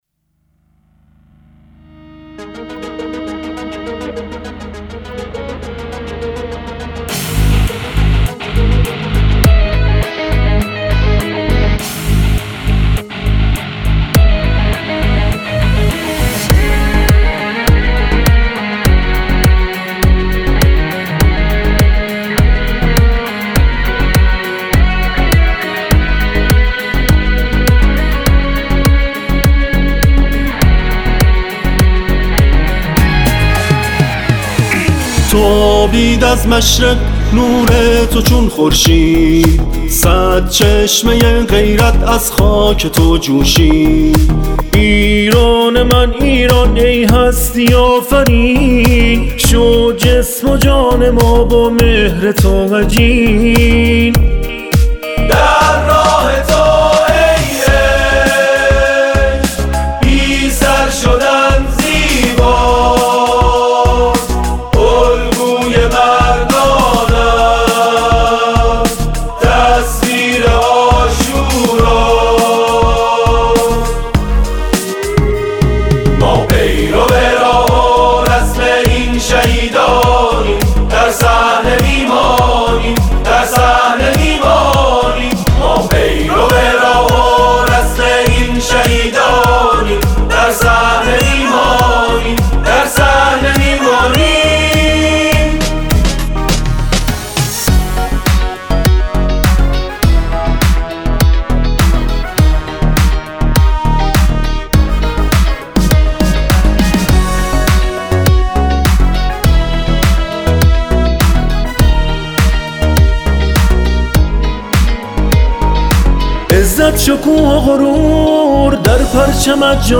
به مناسبت دهه فجر؛